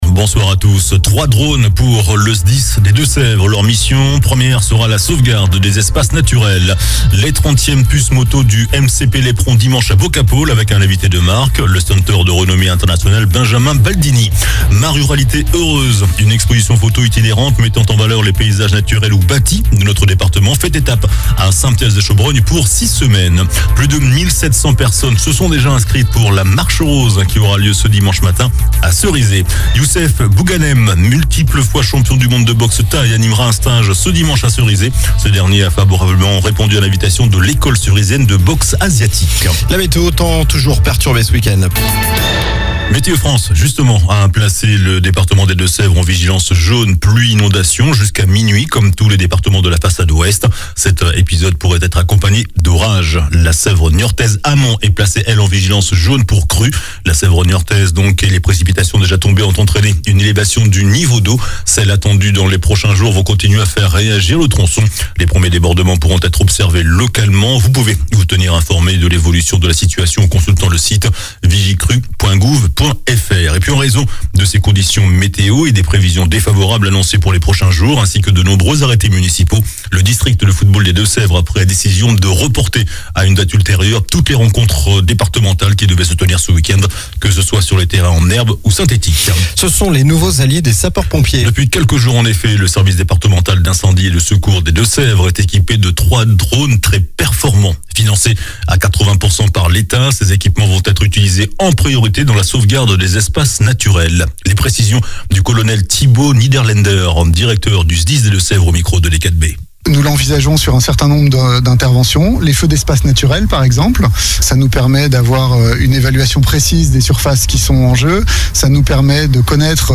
JOURNAL DU VENDREDI 27 OCTOBRE ( SOIR )